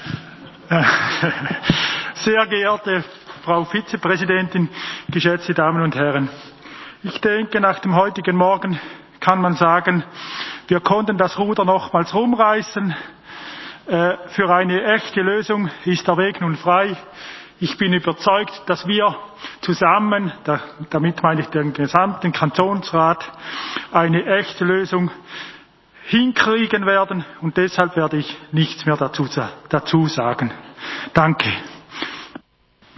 20.4.2021Wortmeldung
Session des Kantonsrates vom 19. und 20. April 2021